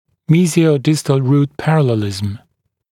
[ˌmiːzɪəu’dɪstl ruːt ‘pærəlelɪzəm][ˌми:зиоу’дистл ру:т ‘пэрэлэлизэм]параллельность корней зубов в мезиодистальном направлении